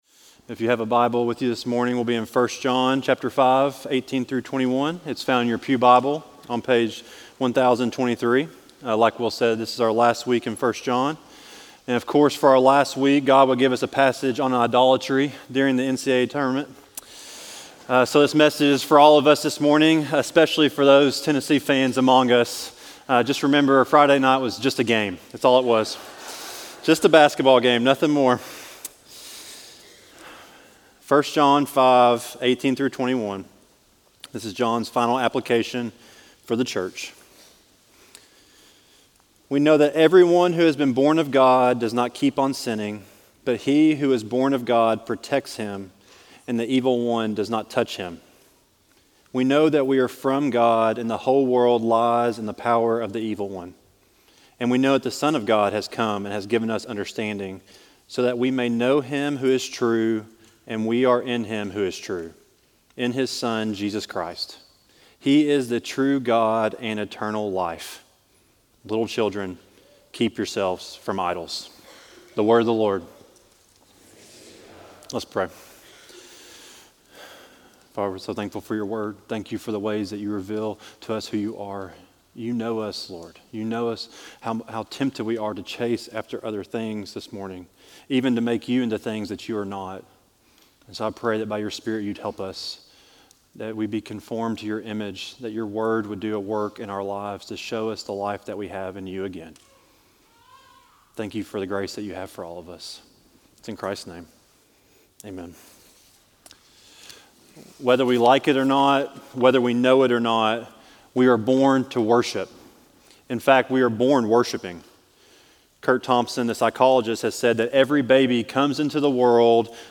Sermons recorded during worship at Tates Creek Presbyterian Church (PCA) in Lexington, KY